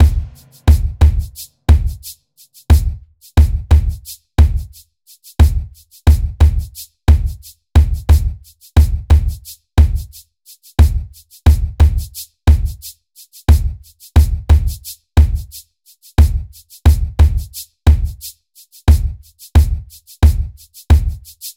03 drums C.wav